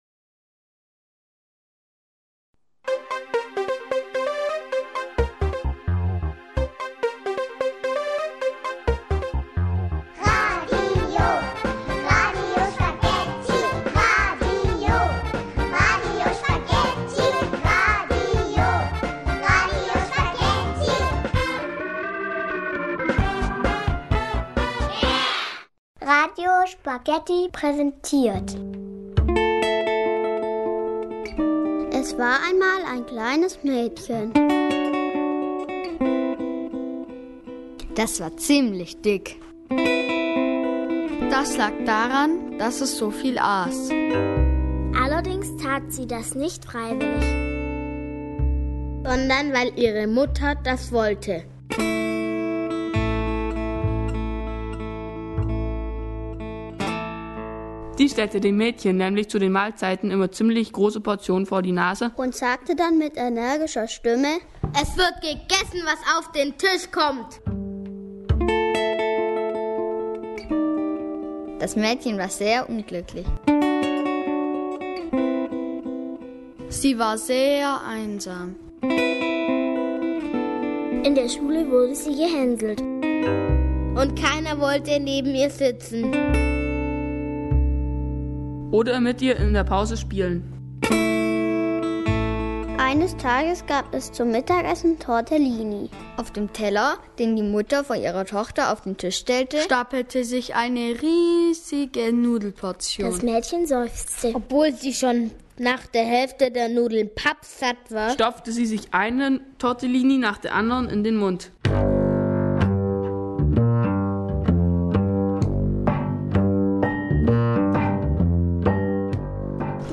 Minimovies und Hörfilme für Kinder